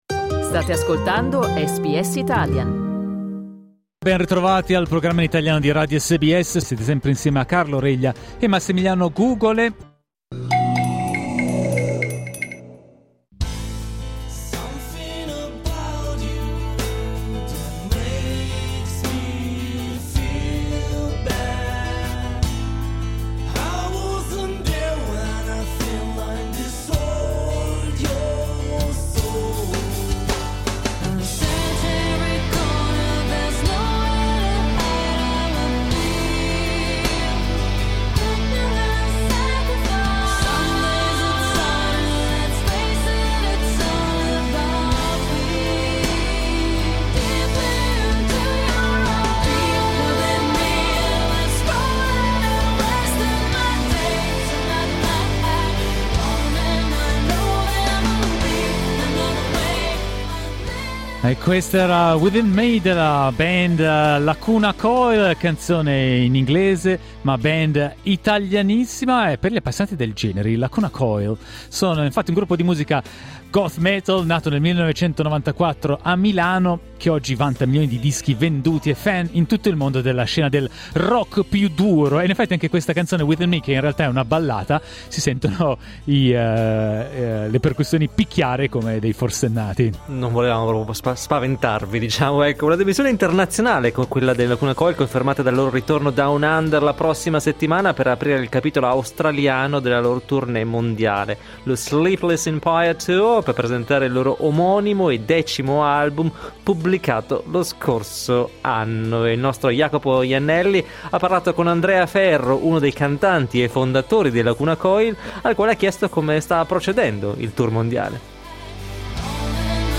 Un successo internazionale a cui i Lacuna Coil sono ben abituati, come conferma Andrea Ferro, uno dei cantanti e fondatori della band, ai microfoni di SBS Italian.